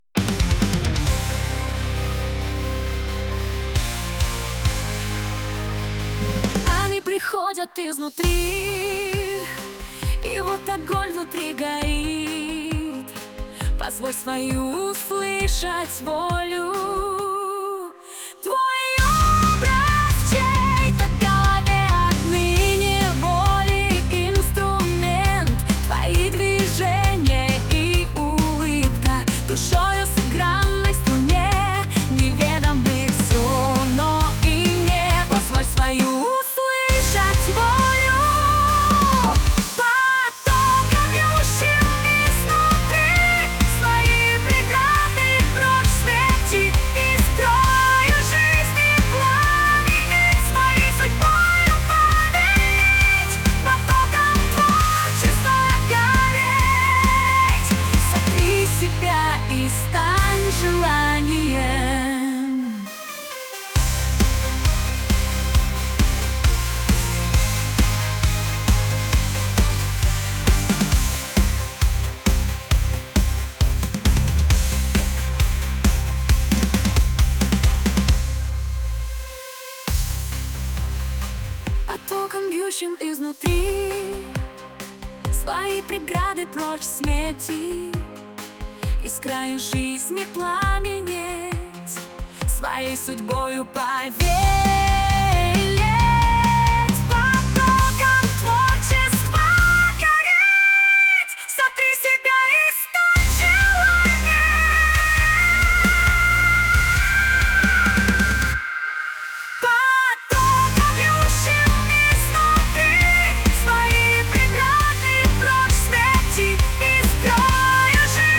Есть что то более нейтральное, в стиле аниме, про психоз вдохновляющий)))